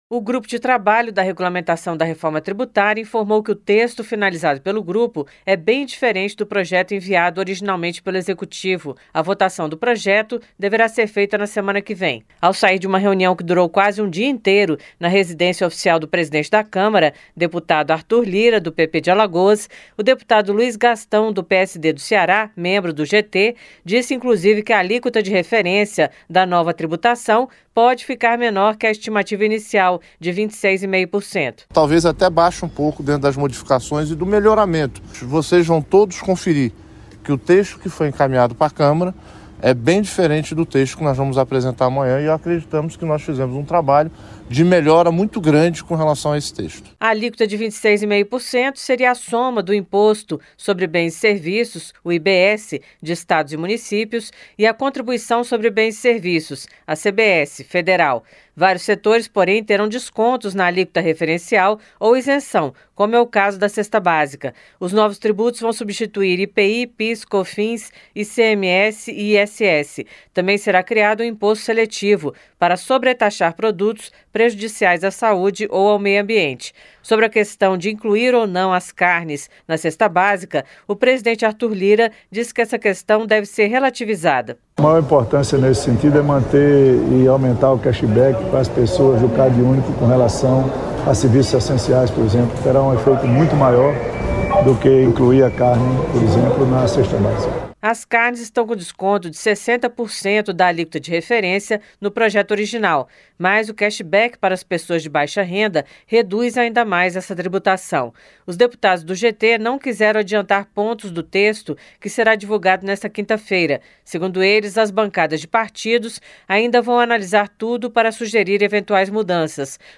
GRUPO DE TRABALHO FINALIZA REGULAMENTAÇÃO DA REFORMA TRIBUTÁRIA COM VÁRIAS MODIFICAÇÕES. A REPÓRTER